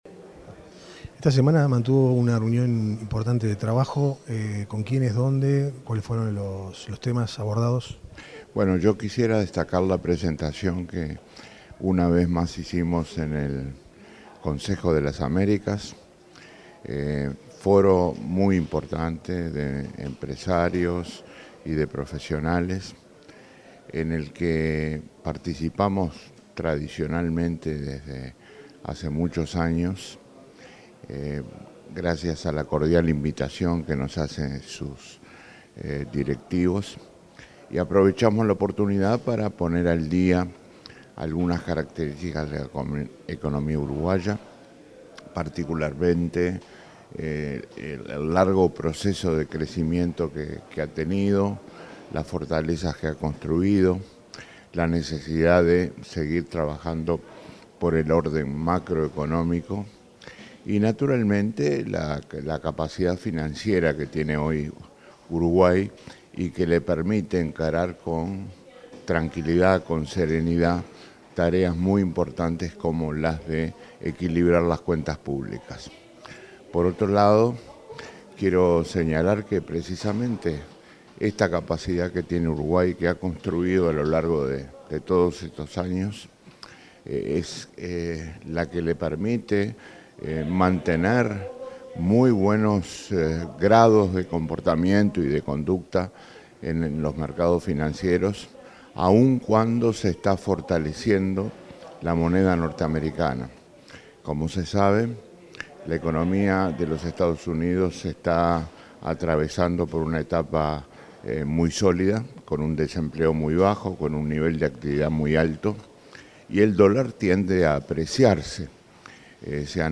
“Si se observa la cotización y el rendimiento de los bonos a escala global, se percibe claramente que Uruguay está sorteando los vaivenes internacionales con mucho éxito”, precisó el ministro de Economía, Danilo Astori, ante el Consejo de las Américas. En diálogo con la Secretaria de Comunicación en Nueva York, resaltó que la solidez construida en los últimos años permite enfrentar firmemente la apreciación del dólar.